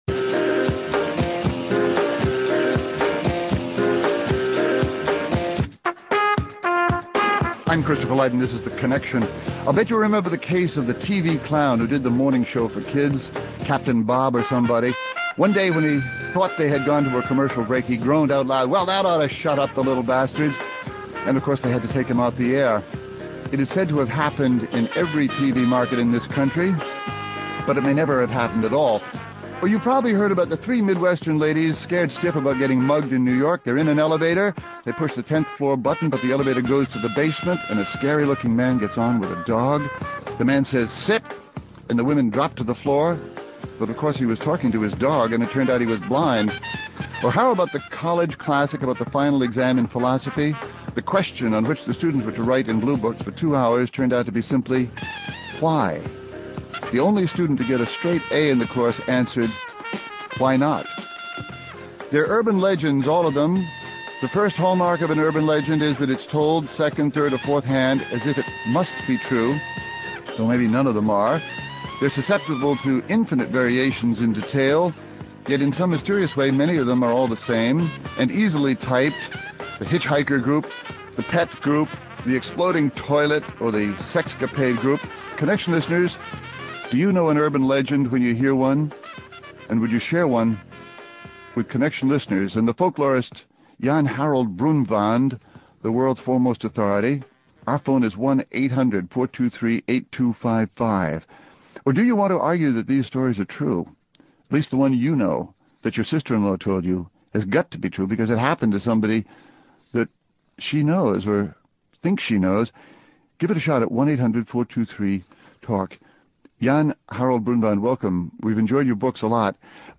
(Hosted by Christopher Lydon)